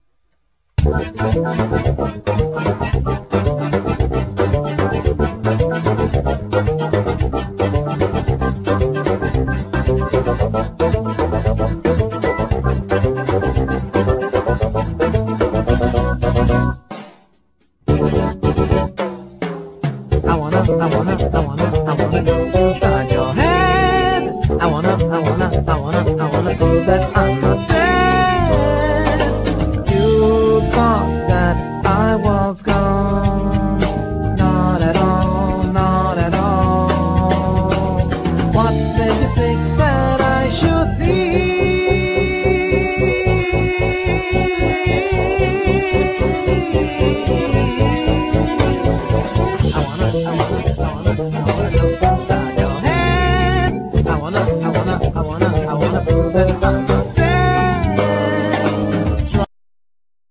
Vocal,Synthsizer,Harmonica
Drums,Keyboards
Guitar,Mandlin
Bass,Melodica
Cello,Bass